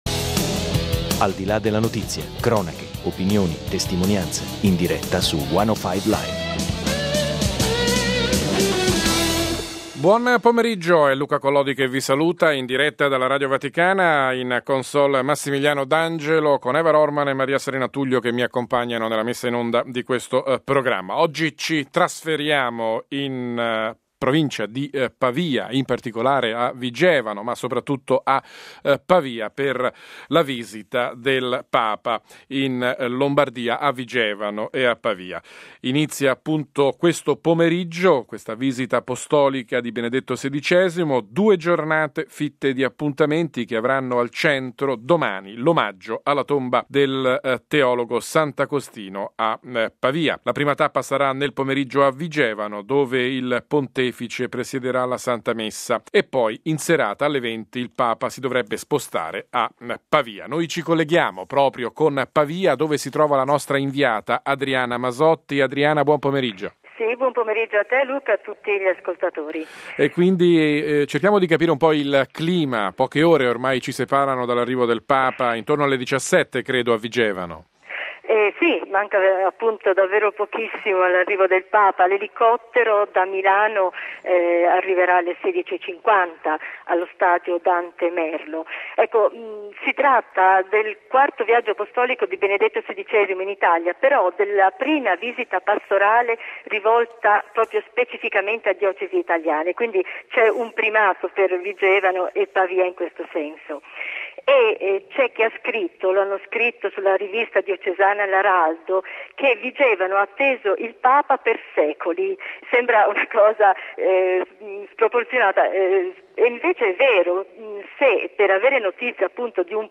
Campane a festa per l'arrivo del Papa in pianura padana. Benedetto XVI visita ufficialmente le prime due diocesi italiane, Vigevano e Pavia, dopo Bari, Manoppello (Chieti) e Verona.